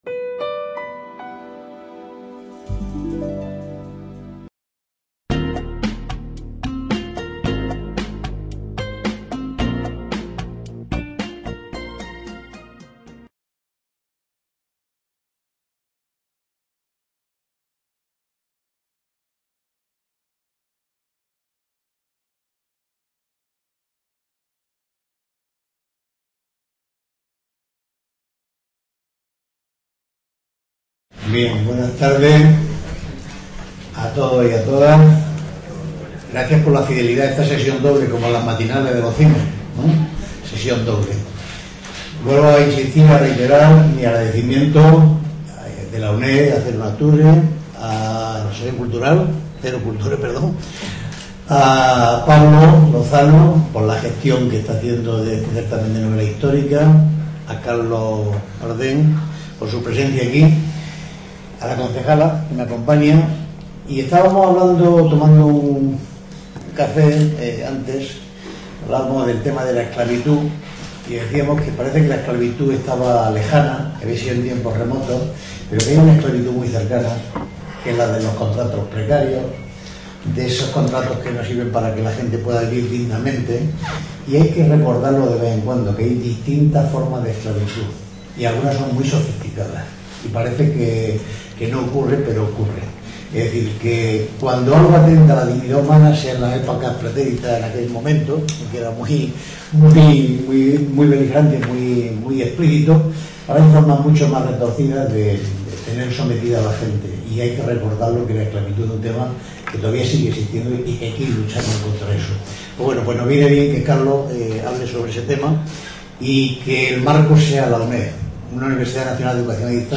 En el contexto de la VIII Edición del Certamen Internacional de Novela Histórica Ciudad de Úbeda (2019), el escritor Carlos Bardem mantiene un encuentro literario con sus lectores en el Centro Asociado de la UNED en Úbeda, para presentar su novela "Mongo blanco".